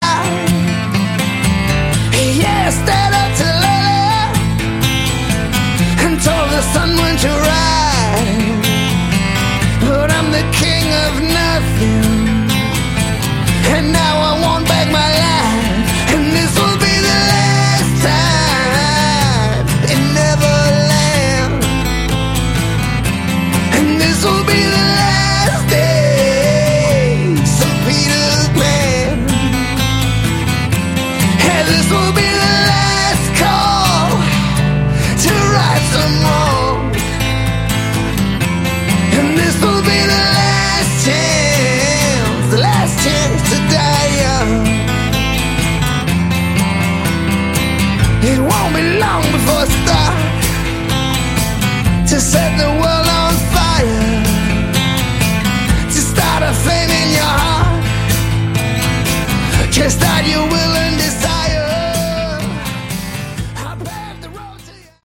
Category: Hard Rock
Vocals, Guitar
Drums
Bass
acoustic